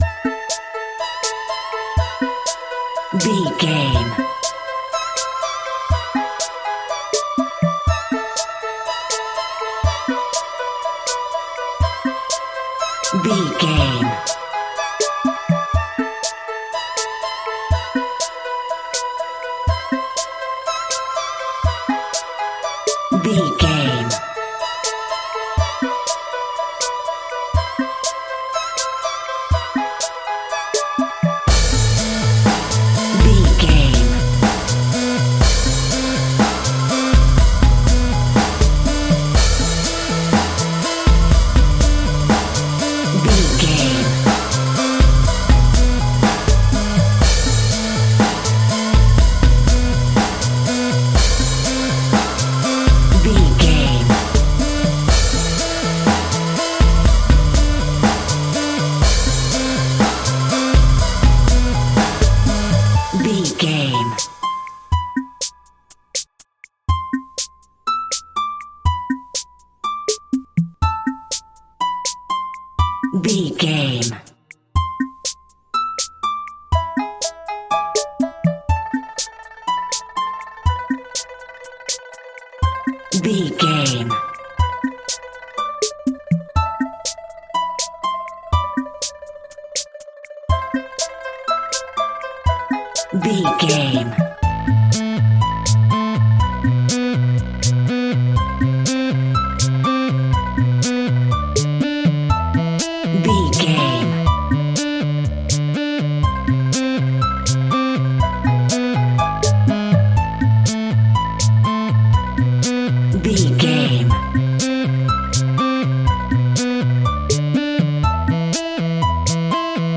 In-crescendo
Aeolian/Minor
scary
ominous
dark
suspense
eerie
energetic
piano
synthesiser
drum machine
percussion
pads